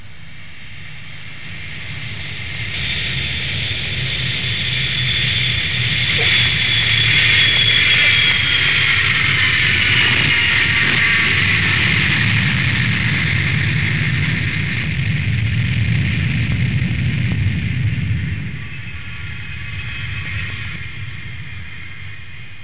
دانلود آهنگ طیاره 17 از افکت صوتی حمل و نقل
جلوه های صوتی
دانلود صدای طیاره 17 از ساعد نیوز با لینک مستقیم و کیفیت بالا